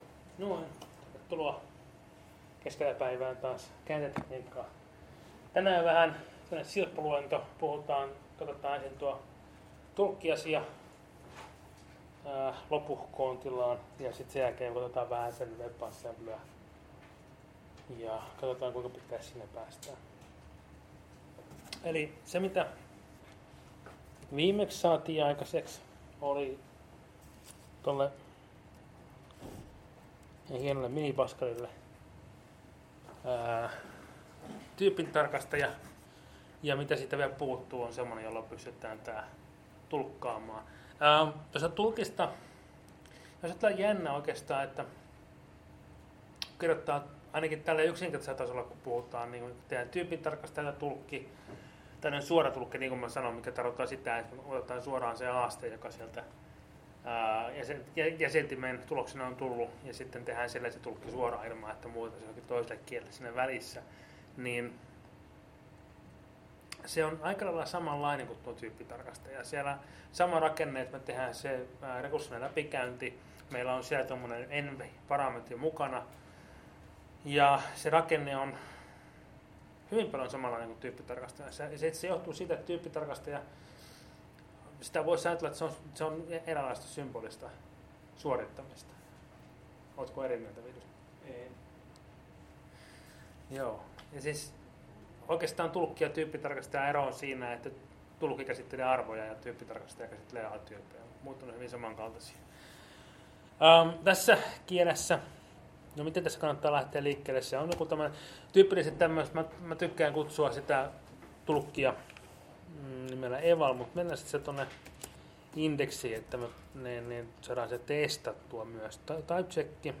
Luento 7